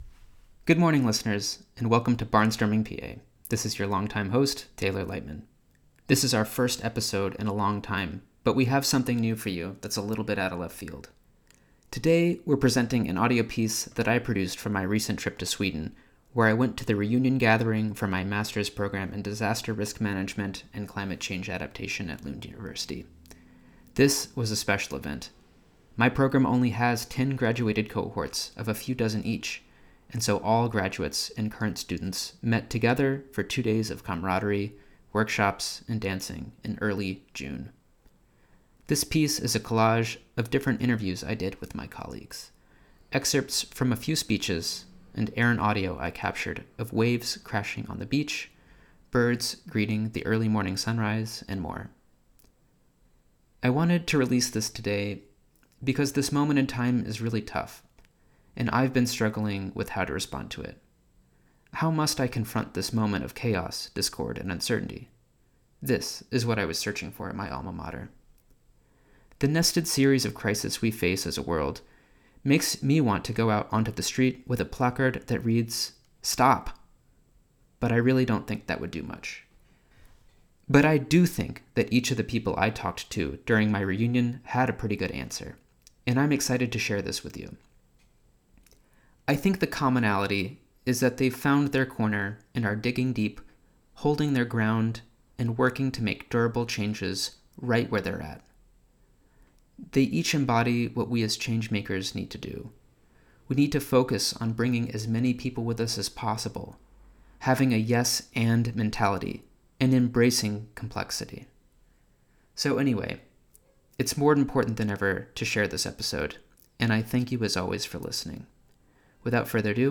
This piece is a collage of different interviews I did with my colleagues, excerpts from a few speeches, and...